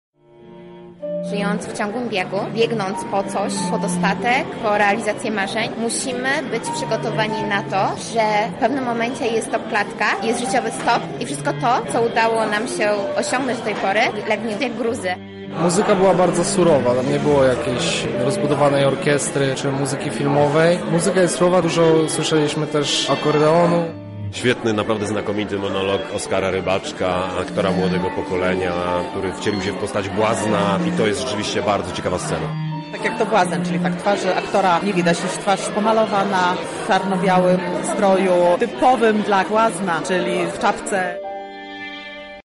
O refleksjach na temat filmu opowiedzieli uczestnicy: